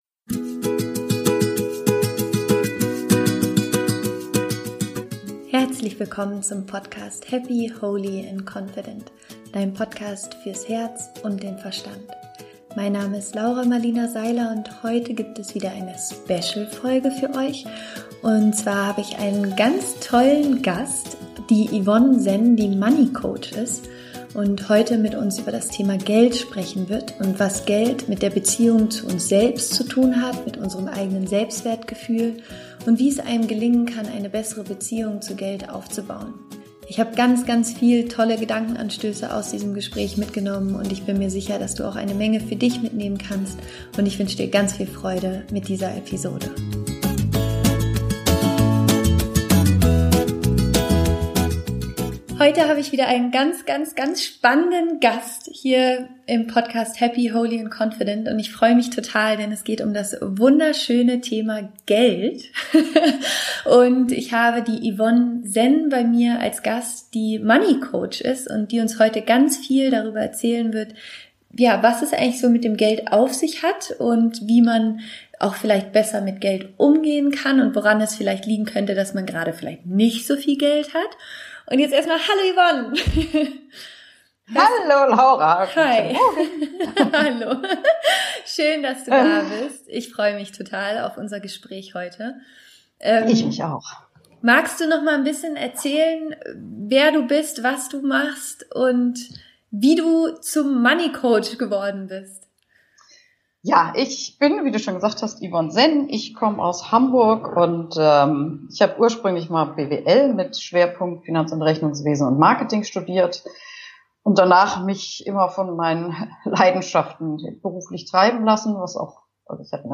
Wie du Geld lieben lernen kannst - Interview Special